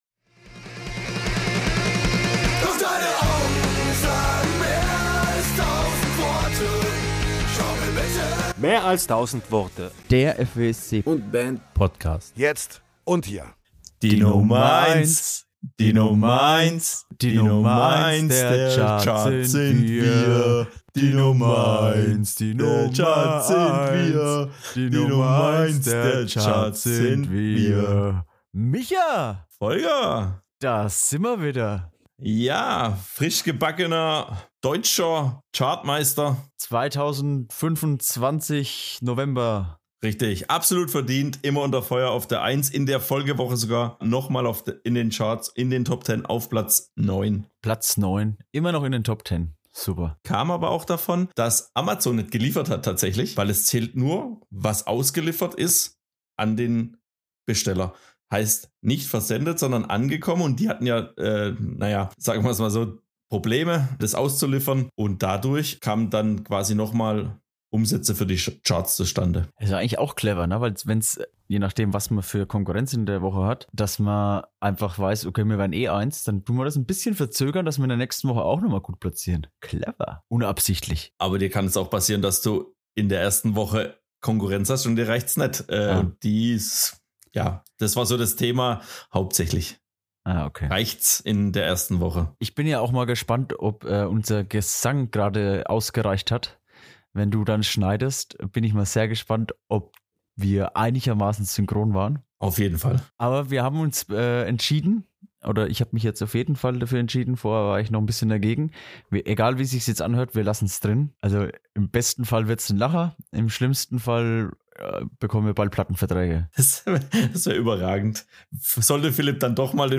Dazu zwei überaus sympathische Gäste.